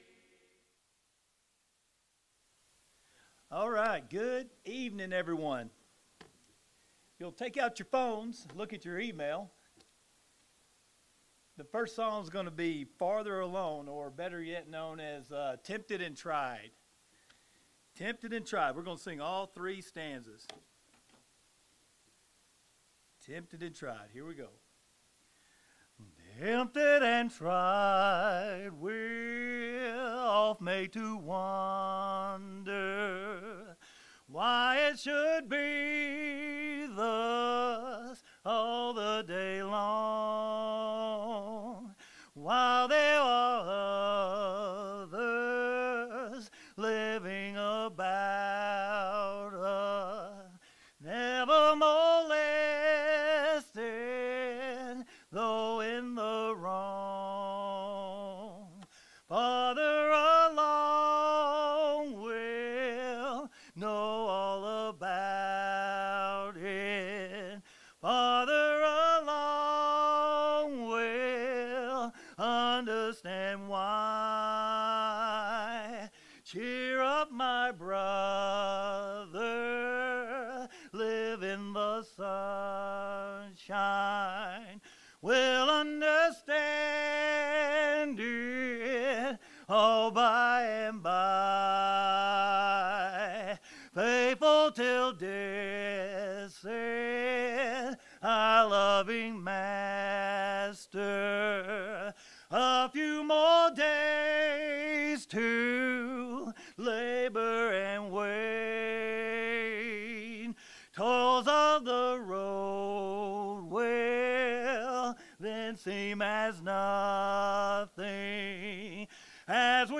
Proverbs 23:23, English Standard Version Series: Sunday PM Service